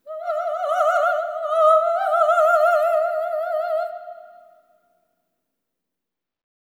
OPERATIC14-L.wav